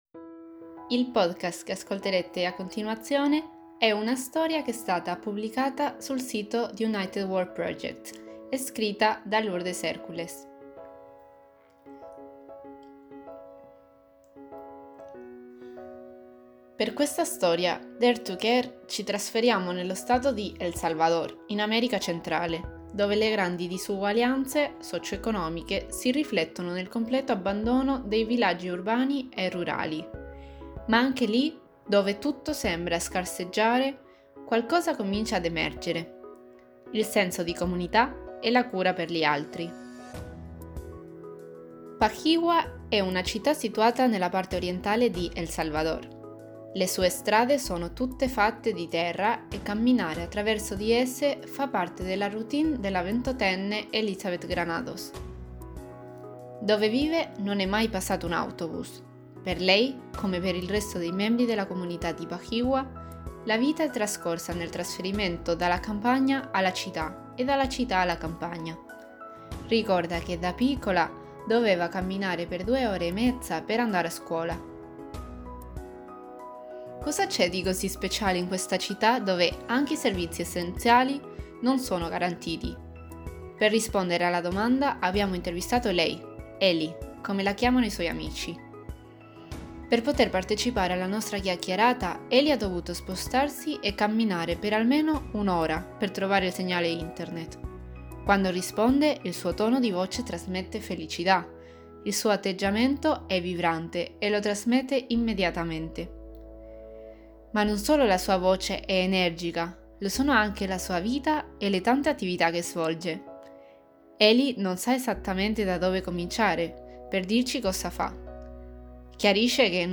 Ambiente > Audioletture
Music: Bendsound